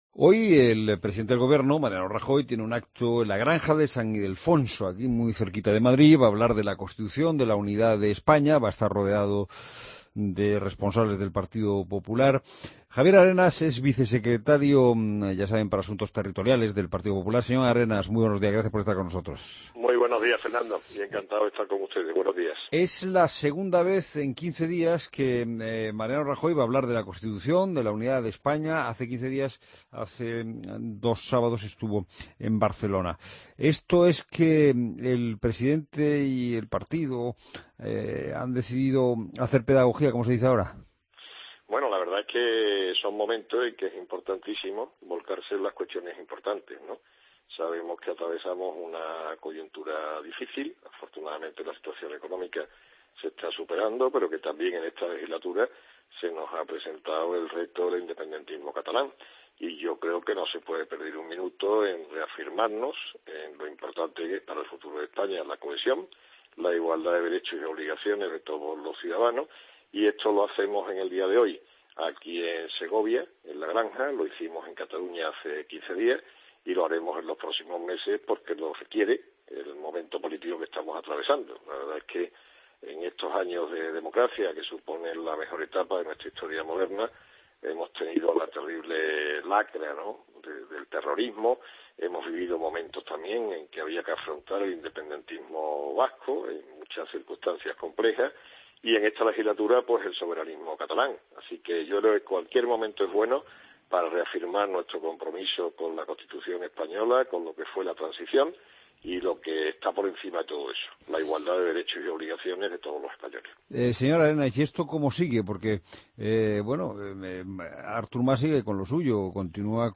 Entrevista a Javier Arenas en La Mañana Fin de Semana